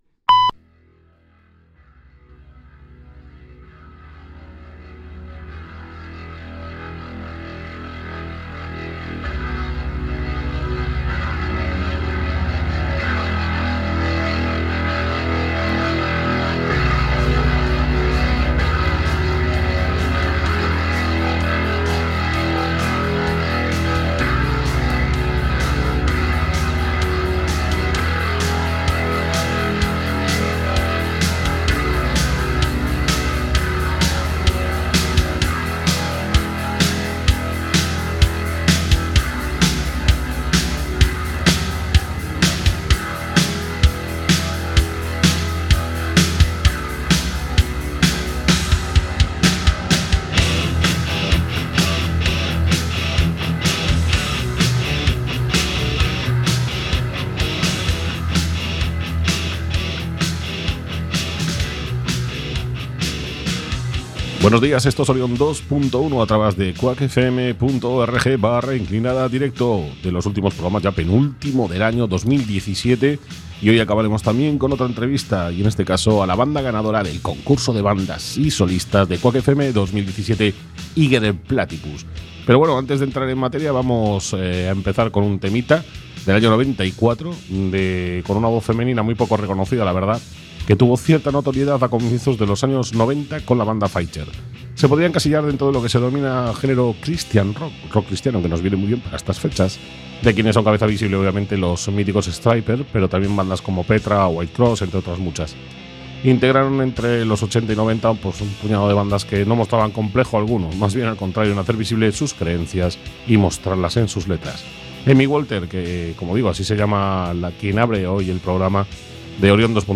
Programa de Rock y heavy en todas sus vertientes con un amplio apartado de agenda, de conciertos y eventos, en la ciudad y Galicia. Entrevistas, principalmente a bandas gallegas, y repleto de novedades discográficas.